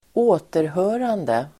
återhörande substantiv, Uttal: [²'å:terhö:rande] Idiom: på återhörande!